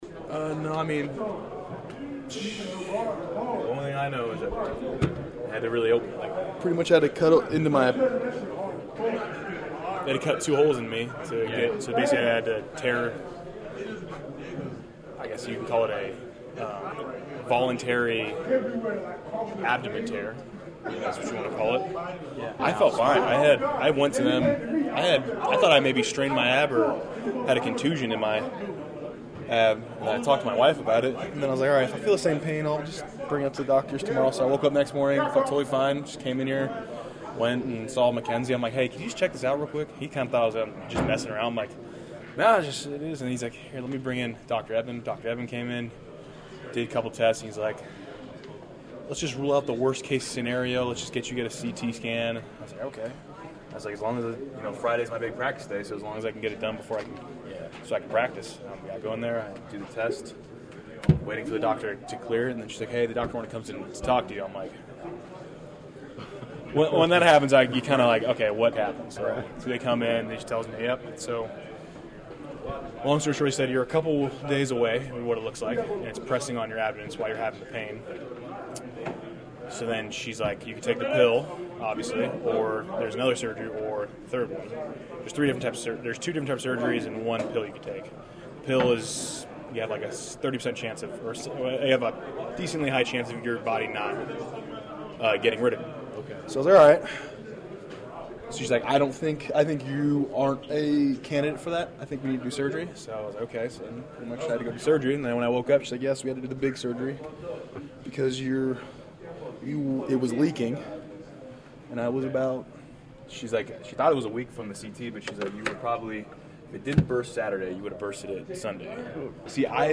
While Head Coach Matt LaFleur didn’t rule him out, it’s a real long shot and after practice, I was among a couple of other reporters who got the lowdown on how David went down with appendicitis.
To begin the conversation, Bakhtiari was asked if he had any idea when he might return to duty.